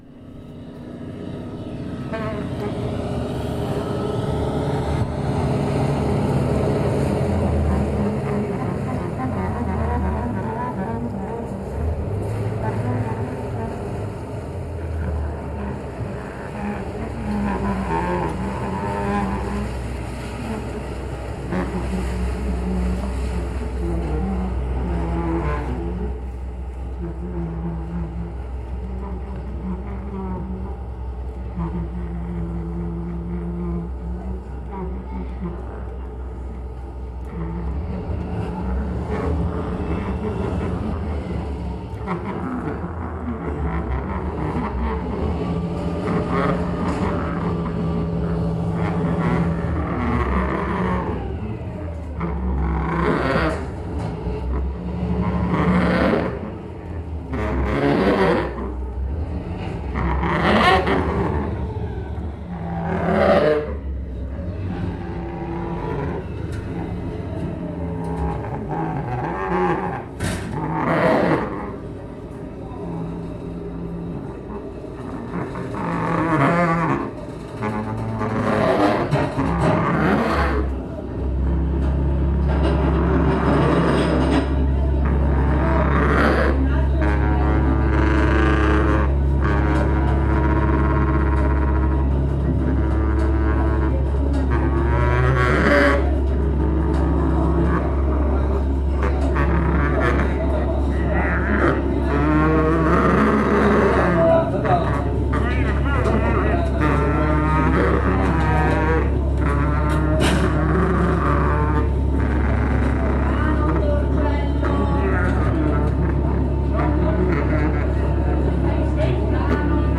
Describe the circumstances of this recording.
A second live improvised remix of our Venetian sound